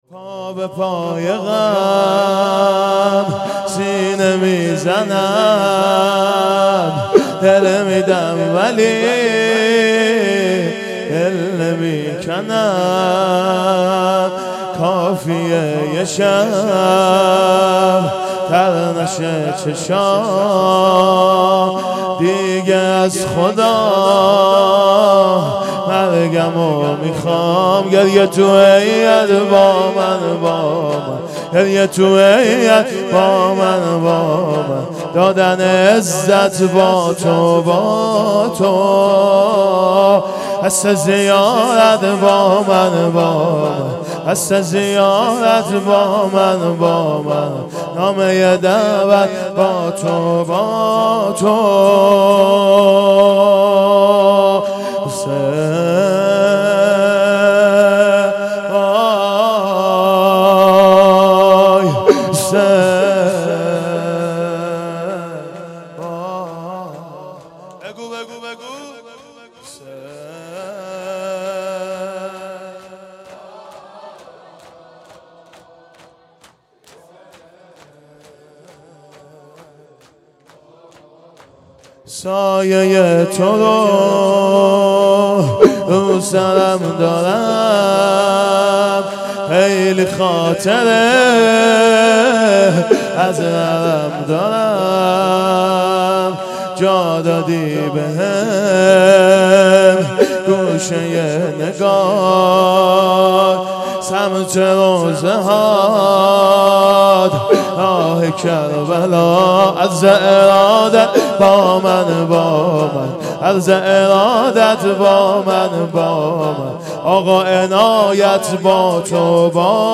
پا به پای غم سینه میزنم _ شور
محرم 1440 _ شب هشتم